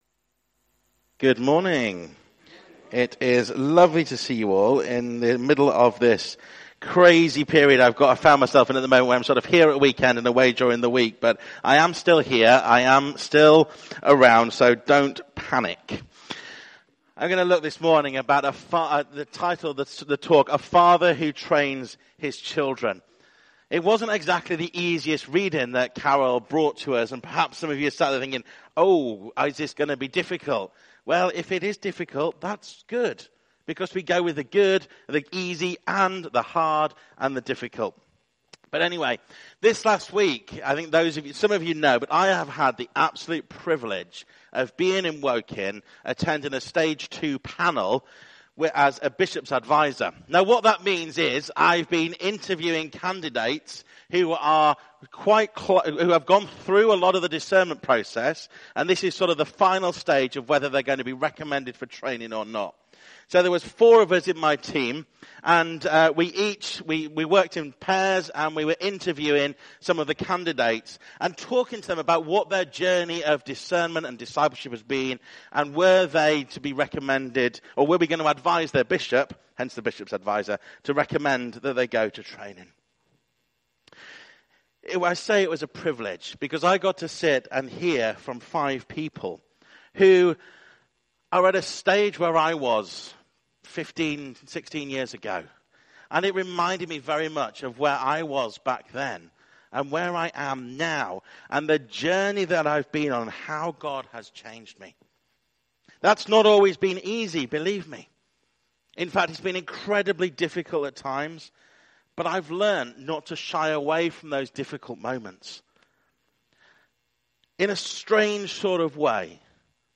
Christchurch Bushmead Sermon Series